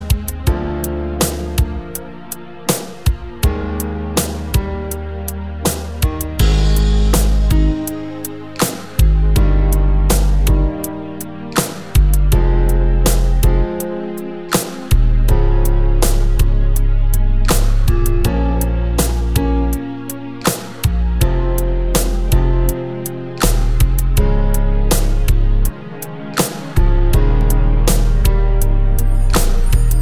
no Backing Vocals Soul / Motown 4:21 Buy £1.50